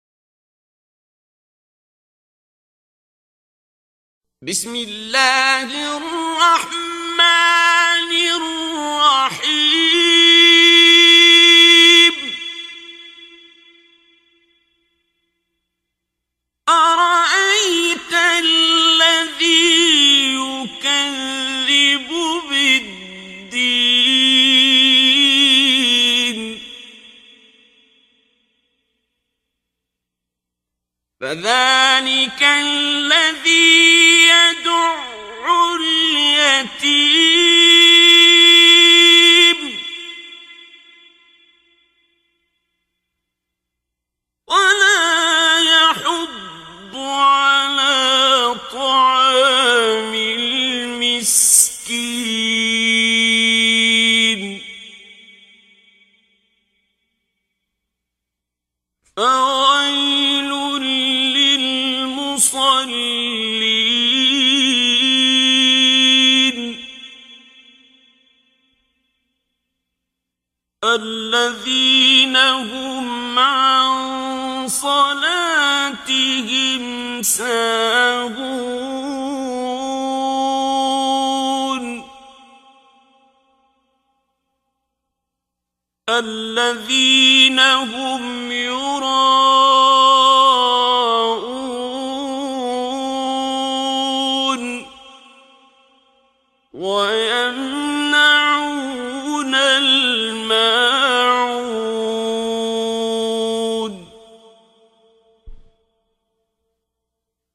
دانلود تلاوت زیبای سوره ماعون آیات 1 الی 7 با صدای دلنشین شیخ عبدالباسط عبدالصمد
در این بخش از ضیاءالصالحین، تلاوت زیبای آیات 1 الی 7 سوره مبارکه ماعون را با صدای دلنشین استاد شیخ عبدالباسط عبدالصمد به مدت 2 دقیقه با علاقه مندان به اشتراک می گذاریم.